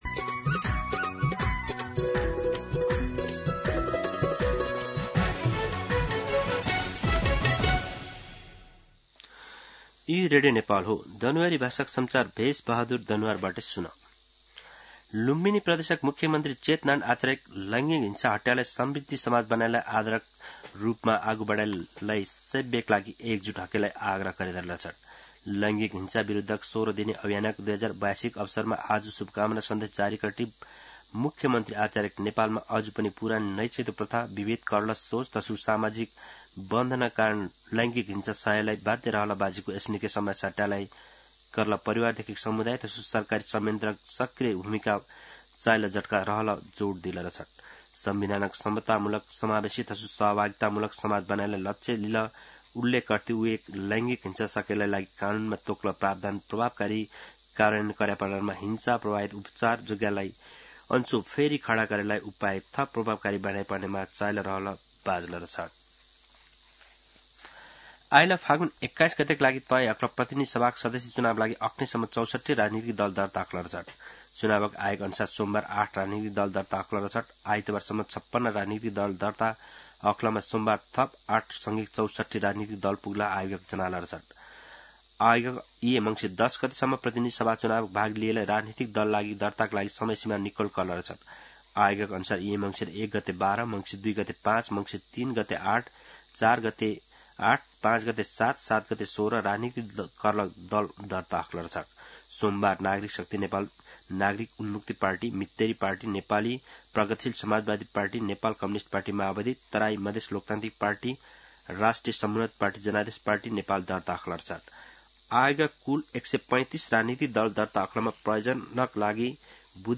दनुवार भाषामा समाचार : ९ मंसिर , २०८२
Danuwar-News-8-9.mp3